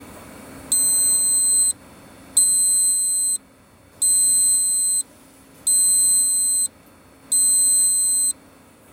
【アナログHDレコーダ】ビープ音が発生する条件について
ビープ音とは：各種イベントの条件が満たされた場合やレコーダに問題が発生した場合に発生する音